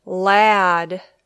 e sound æ sound